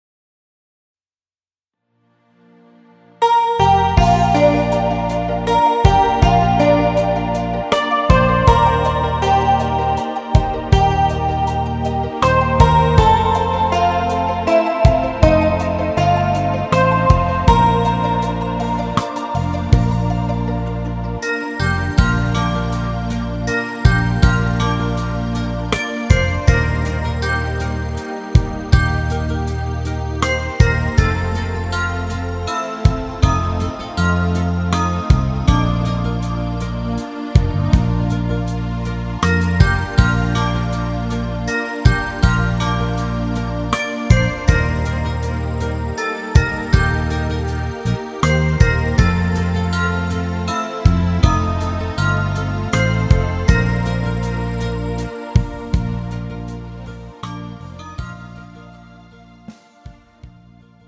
음정 Bb 키
장르 가요 구분 Pro MR